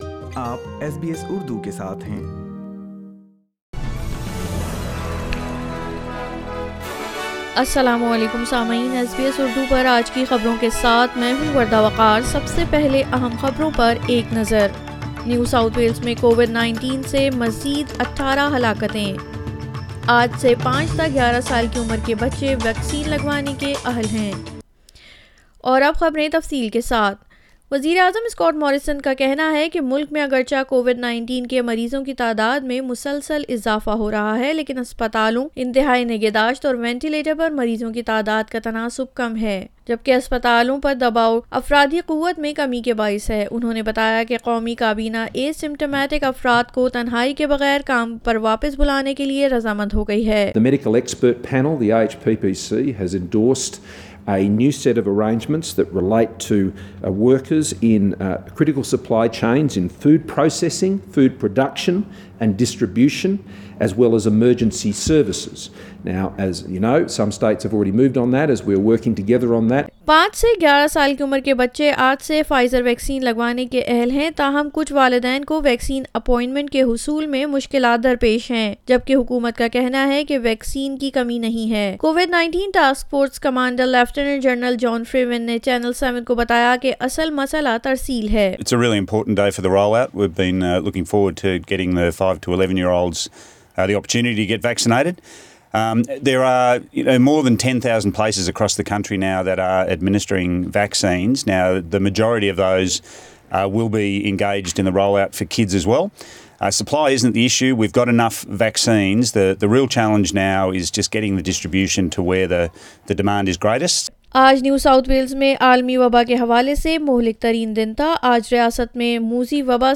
SBS Urdu News 10 January 2022